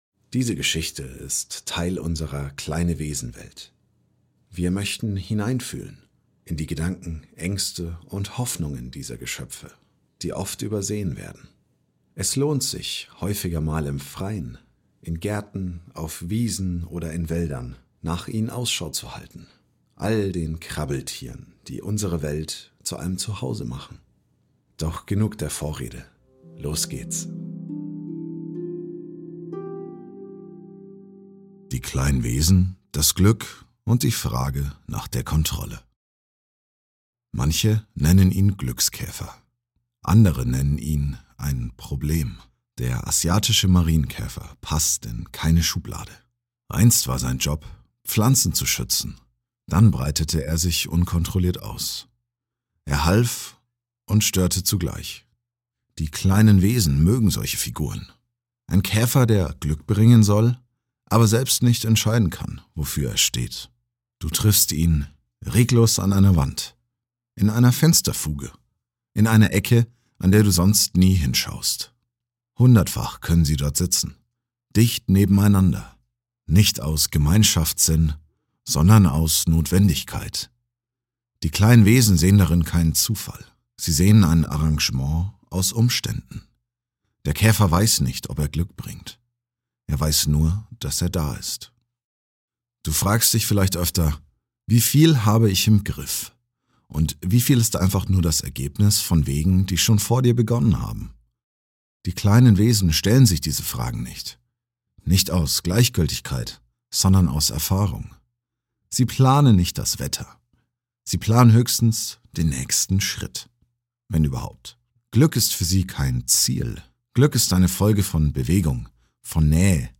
Dieses Hörspiel ist Teil unseres neuen Jahresbegleiters „Kleine